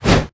su_swing_4.wav